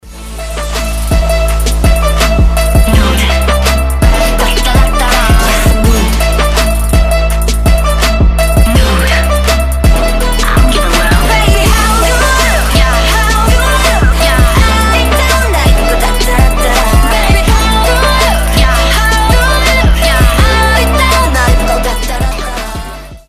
trap
k-pop , ремиксы